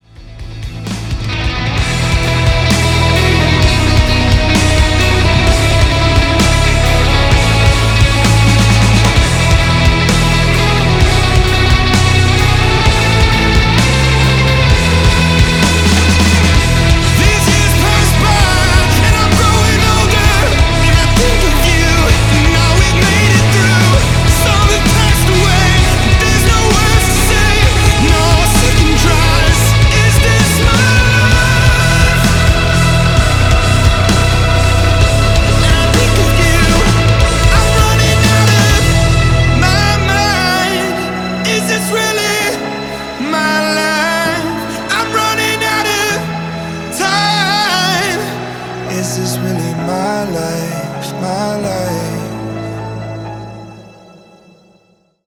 • Качество: 320, Stereo
электрогитара
Alternative Rock
indie rock
post-punk revival
соло